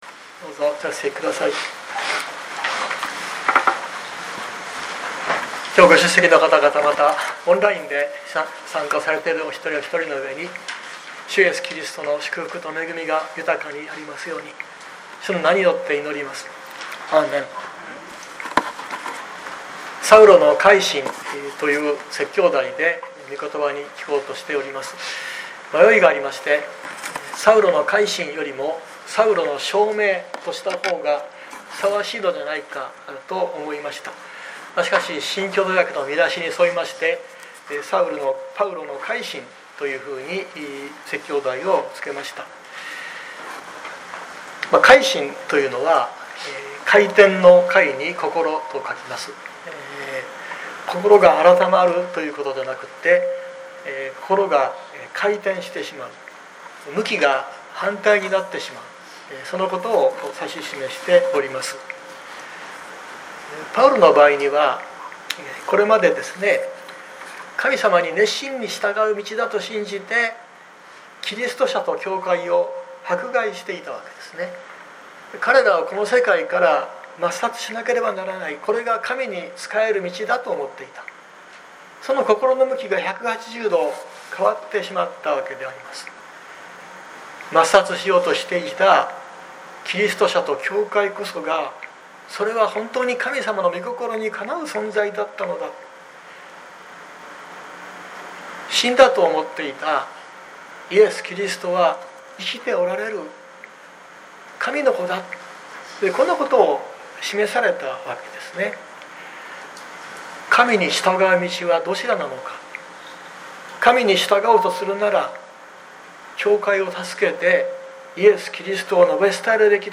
熊本教会。説教アーカイブ。
日曜朝の礼拝